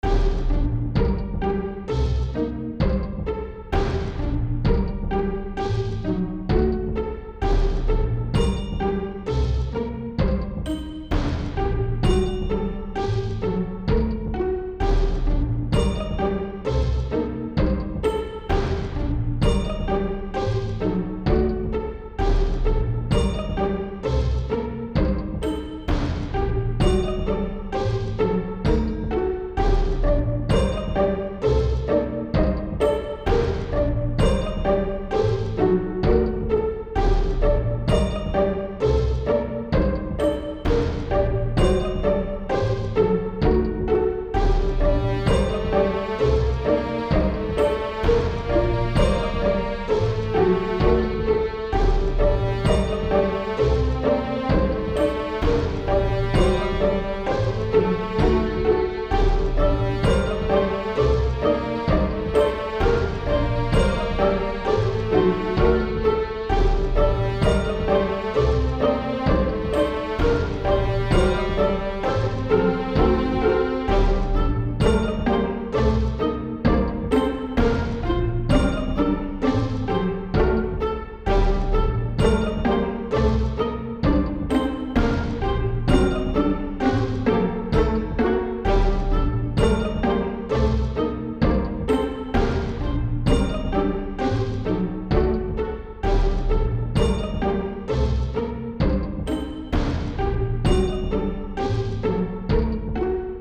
orchestral
Fantasy
cinematic
background
Some more orchestral music :)
Great Dwarven theme.